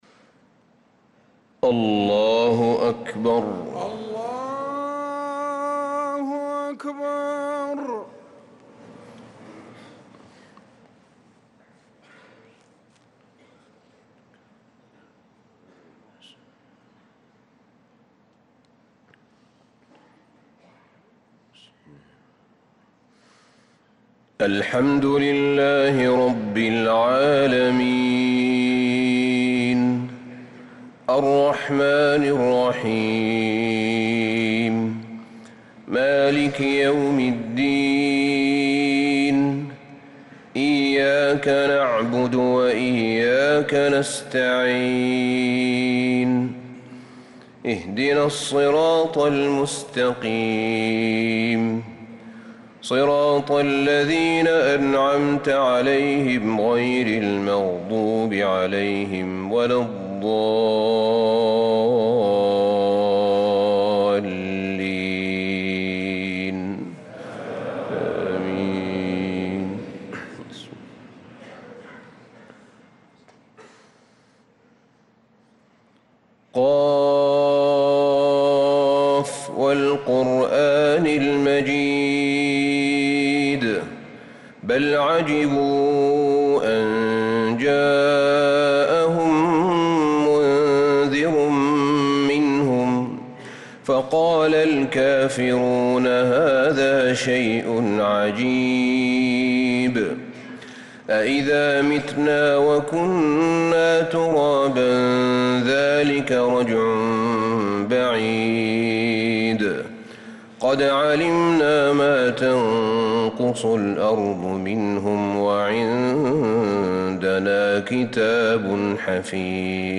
صلاة الفجر للقارئ أحمد بن طالب حميد 26 ذو الحجة 1445 هـ